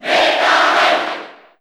Category: Crowd cheers (SSBU) You cannot overwrite this file.
Meta_Knight_Cheer_Japanese_SSB4_SSBU.ogg